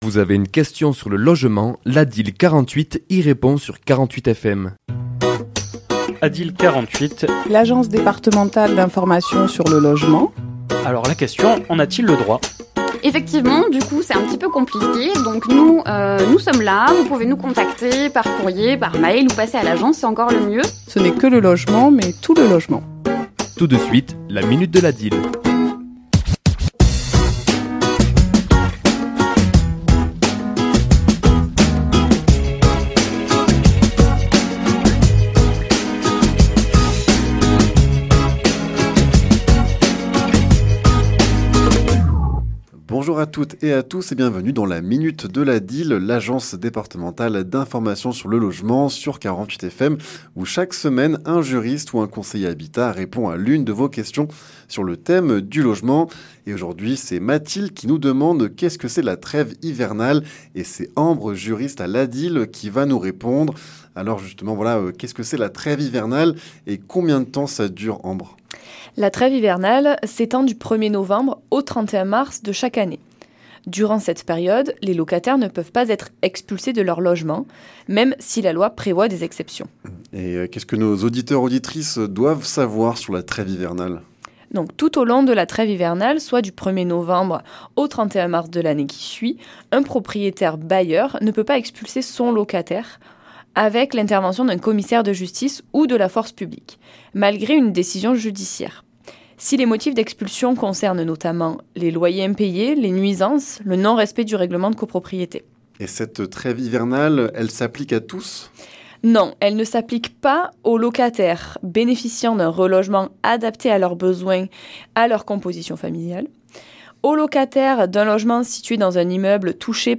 Chronique diffusée le mardi 9 décembre à 11h et 17h10